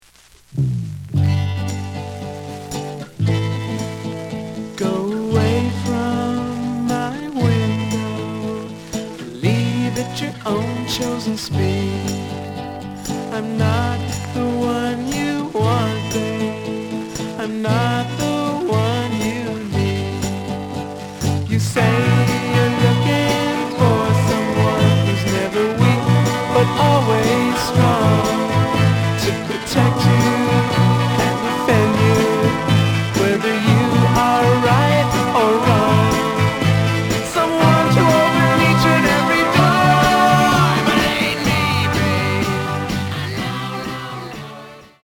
The audio sample is recorded from the actual item.
●Genre: Rock / Pop
Some noise on A side.